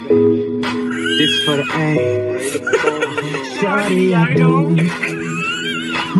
Kermit shawty sound effect download for free mp3 soundboard online meme instant buttons online download for free mp3